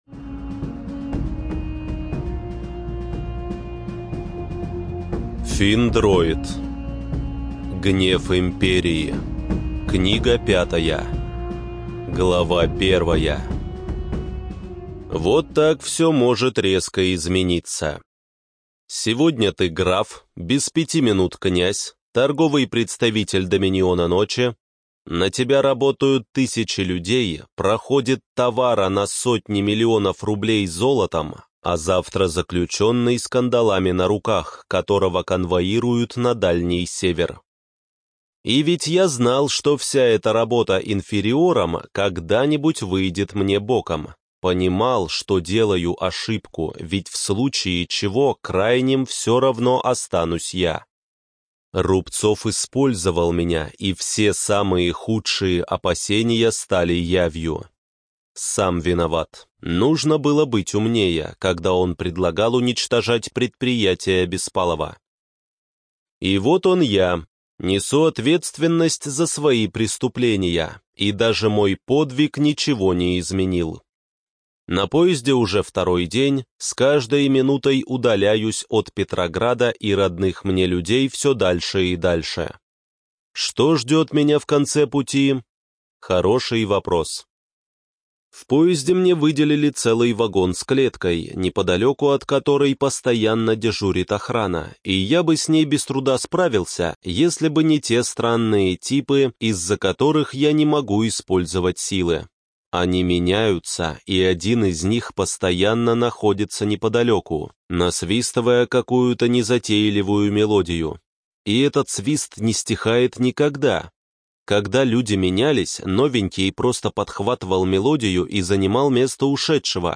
Информация о книге Гнев империи-05 (Библиотека ЛОГОС)